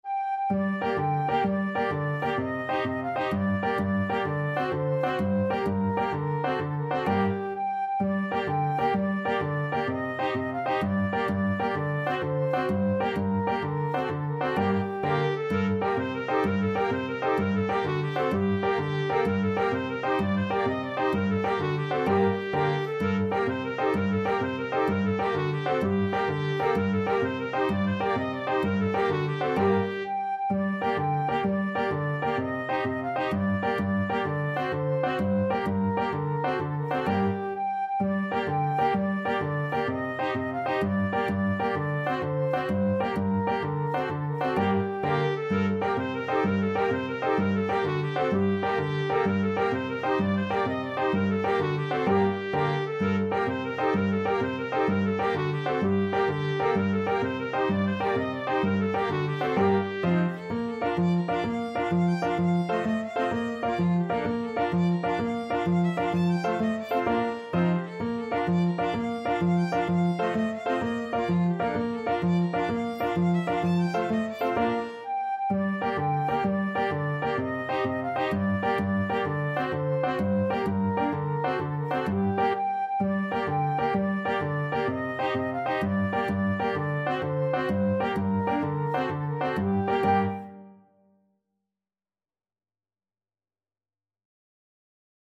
Free Sheet music for Flexible Ensemble and Piano - 4 Players and Piano
Flute
Violin
Guitar (Chords)
Piano
A traditional Neapolitan Italian Tarantella associated with the Southern Italian town of Naples.
G minor (Sounding Pitch) (View more G minor Music for Flexible Ensemble and Piano - 4 Players and Piano )
6/8 (View more 6/8 Music)